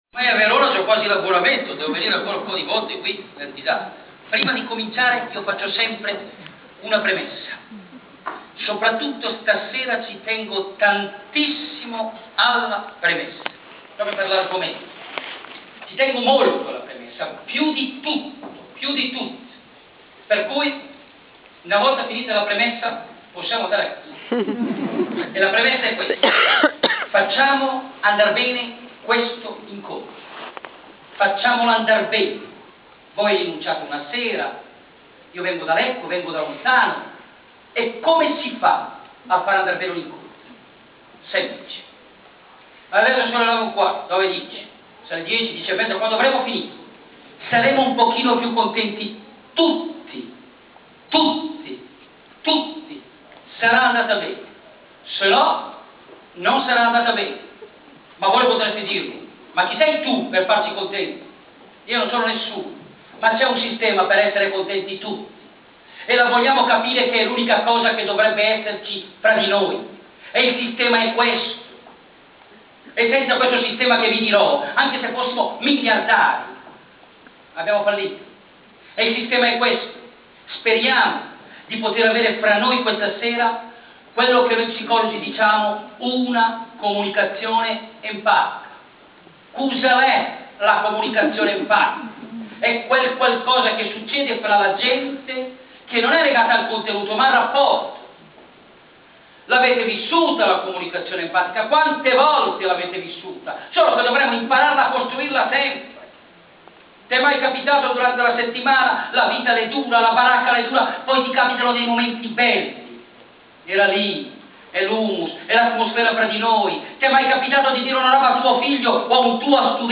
Prospettiva Famiglia “regala” a tutti i suoi sostenitori, per l’ormai prossimo Natale, l’audio-slideshow dell’incontro.